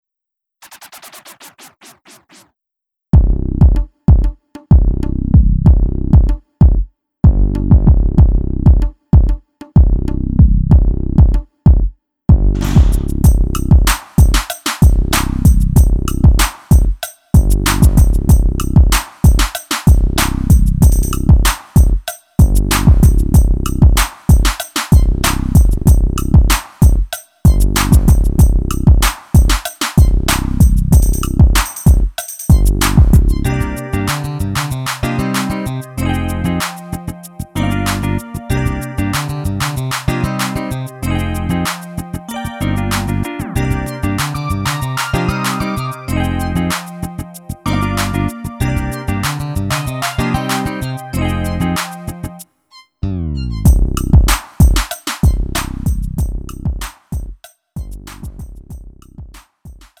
음정 원키 3:15
장르 구분 Lite MR